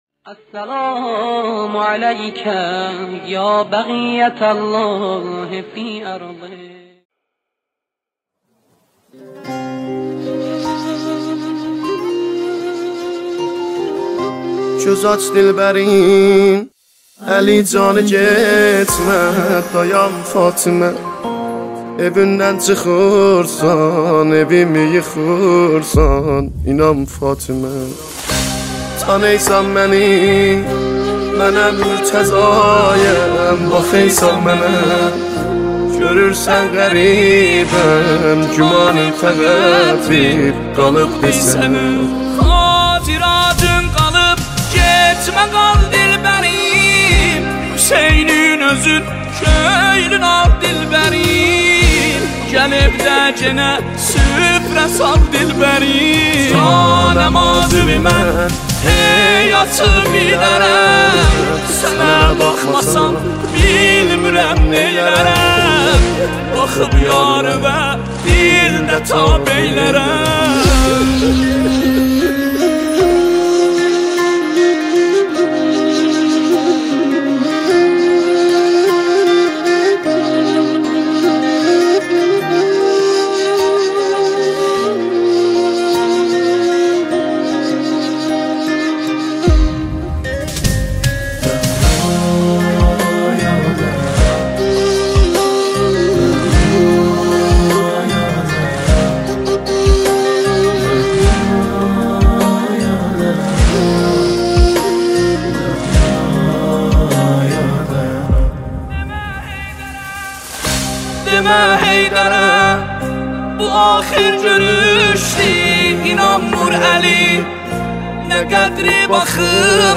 مداحی ترکی
نوای دلنشین